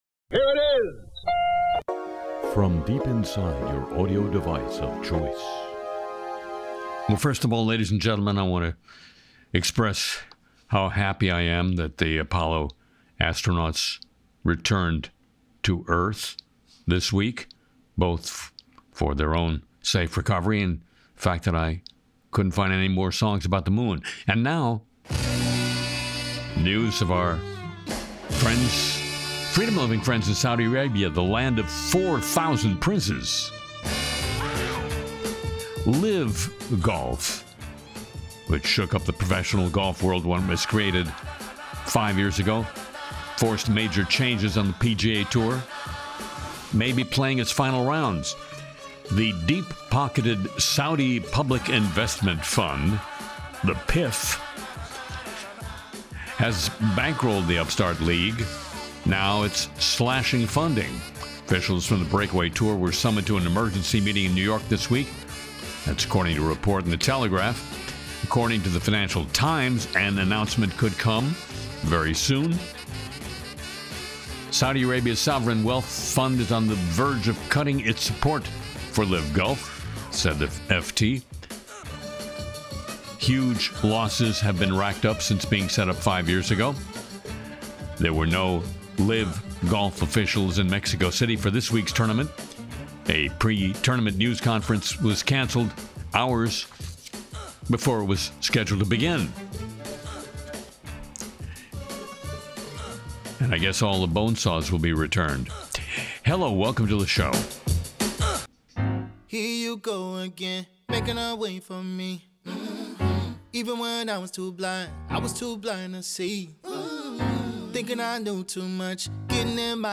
Harry Shearer’s Le Show (Apr 19 2026) features the song Why Shouldn’t I Be The Pope? and a Hegseth skit spoofing a Pentagon prayer moment.
Harry Shearer debuts Why Shouldn't I Be The Pope and spoofs Pete Hegseth’s Pentagon prayer moment in a sharp new sketch.